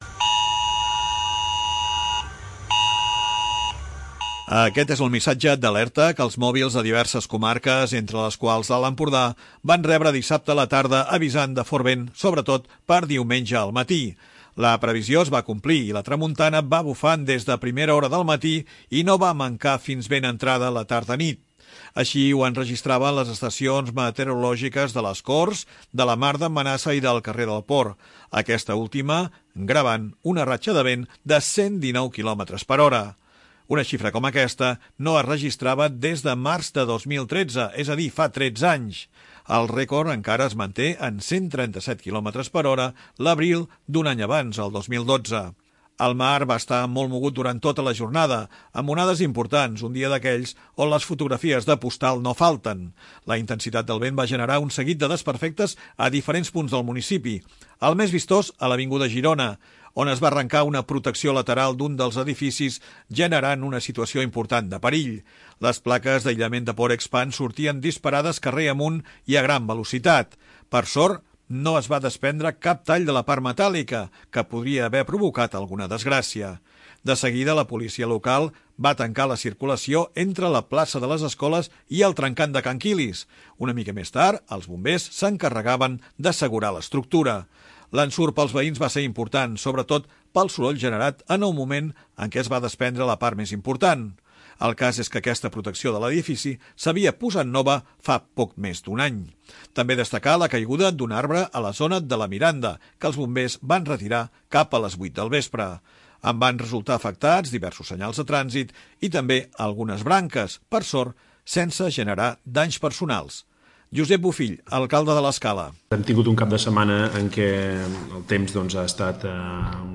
Aquest és el missatge d'alerta que els mòbils de  diverses comarques, entre les quals l'Alt Empordà, van rebre dissabte a la tarda avisant de fort vent, sobretot diumenge al matí.